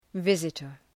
{‘vızıtər}